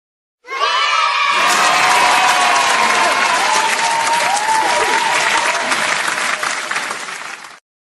Yeah Clap